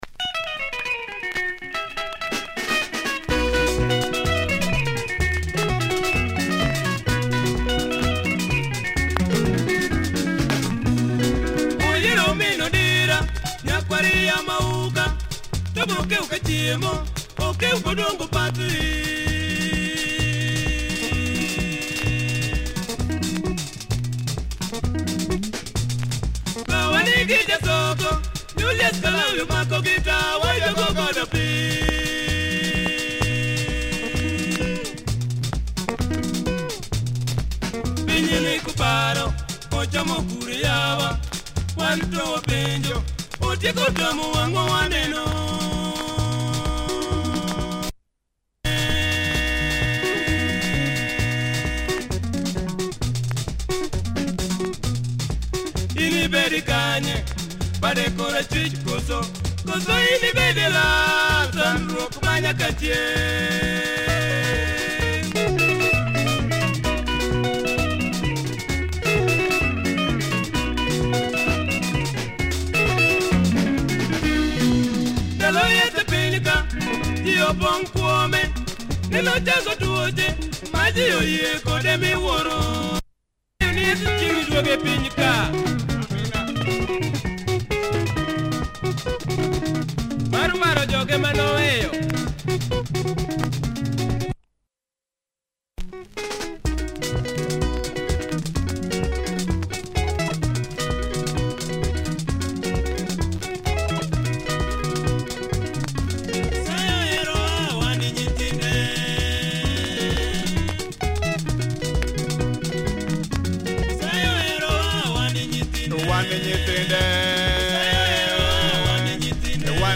Nice LUO benga